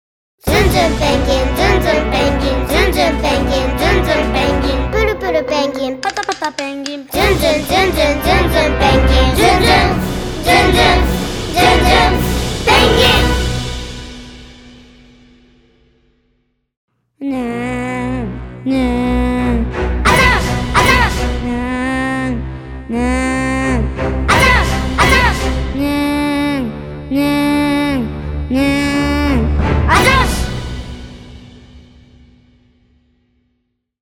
TVCM
SONG ACOUSTIC / CLASSIC